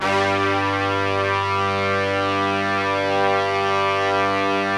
G2 POP BRA.wav